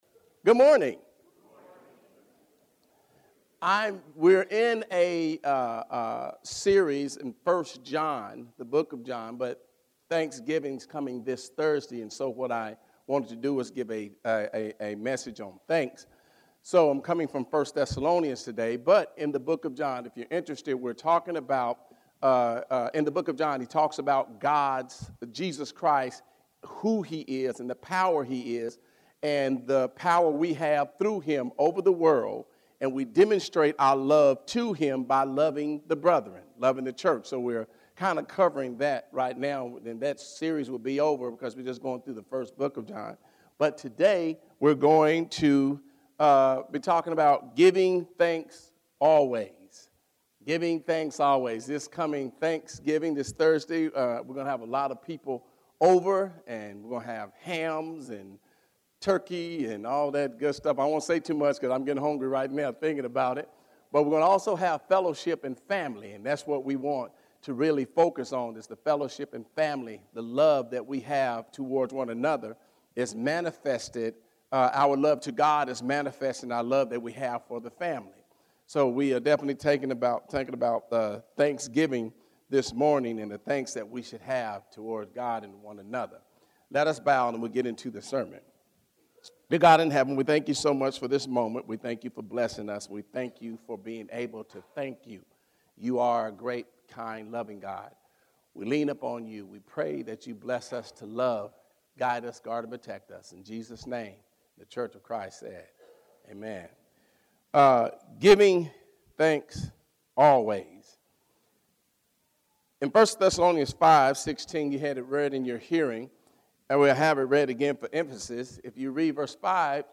Sunday Morning Services | Airport Freeway Church of Christ